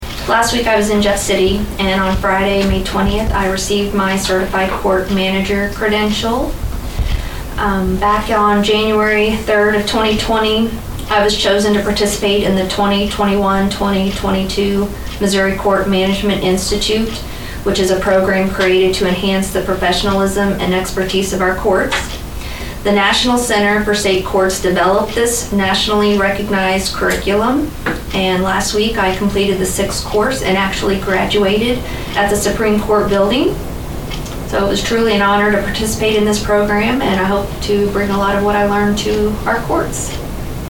Saline County Circuit Clerk Becky Uhlich was at the meeting of the Saline County Commission on Thursday, May 26, and informed commissioners she earned a special credential.